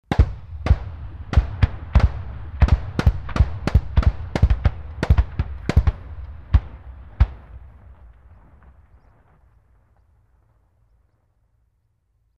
Звуки танка
Грохот выстрелов крупнокалиберного оружия